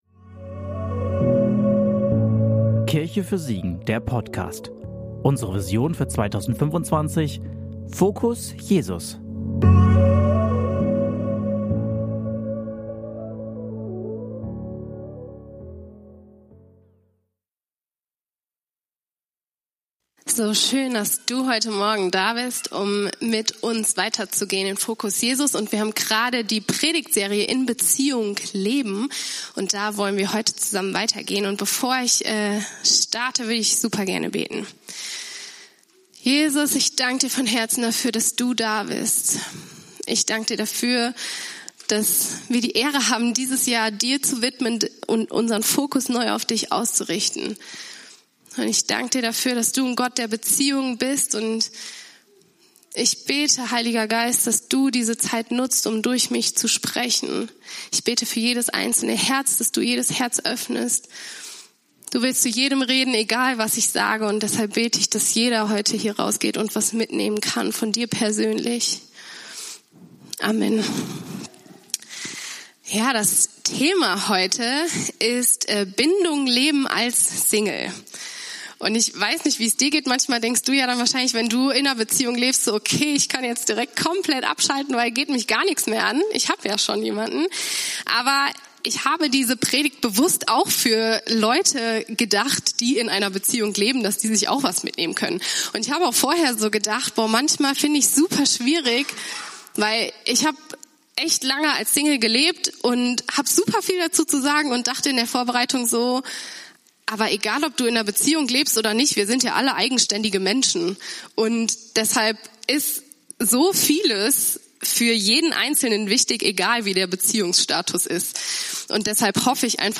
In dieser Predigt geht es darum, wie du als Single aufblühen kannst, wie wir alle in Verbundenheit leben können und welche Schritte dir helfen können mit einer gesunden Perspektive auf deinen Beziehungsstatus zu leben.